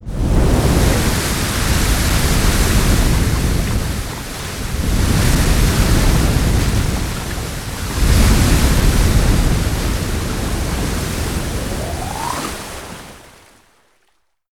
OZ_Geyser_No_Toon.ogg